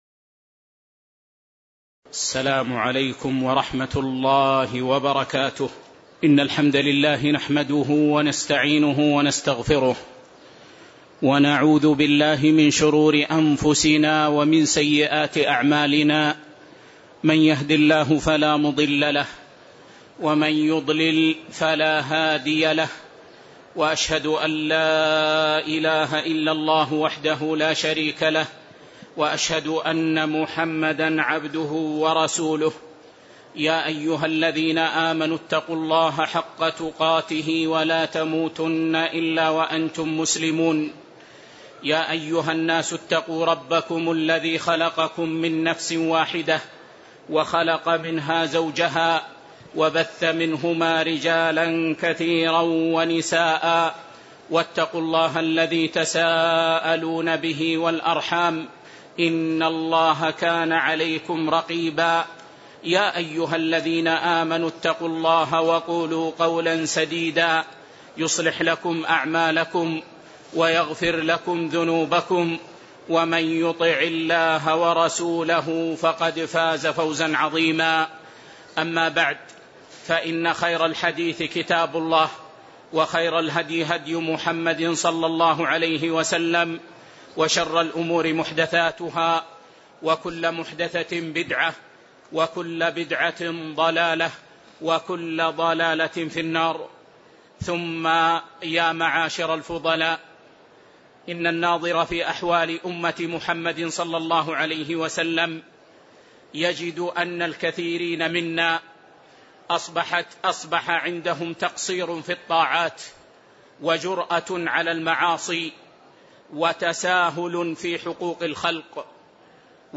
تاريخ النشر ٢٠ ربيع الأول ١٤٣٧ هـ المكان: المسجد النبوي الشيخ